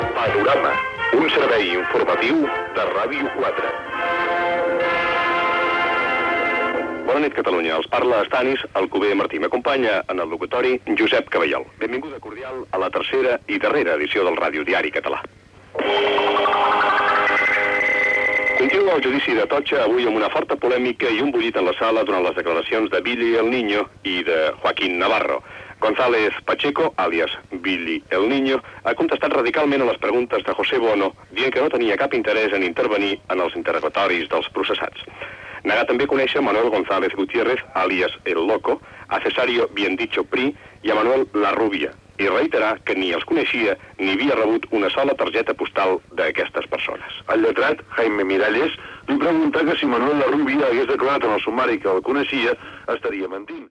Carteta del programa, judici d'Atocha
Informatiu